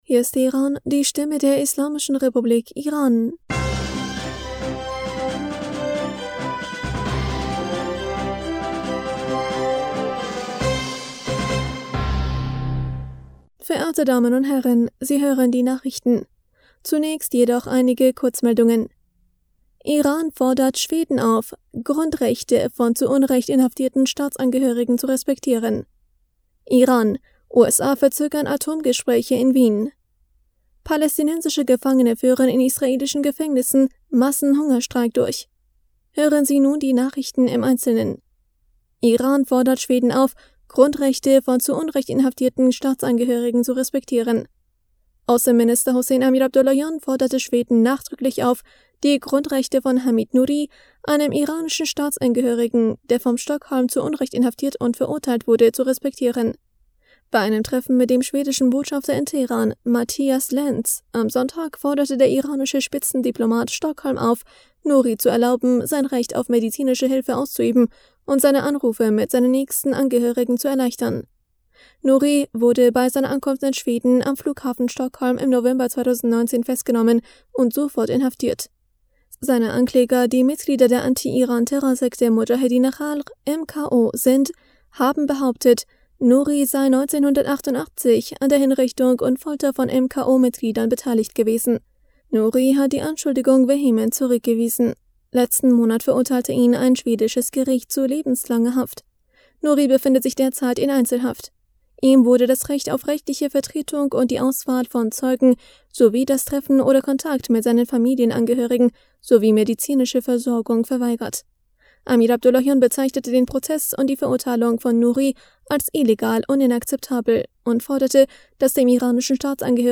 Nachrichten vom 22. August 2022